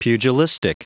Prononciation du mot pugilistic en anglais (fichier audio)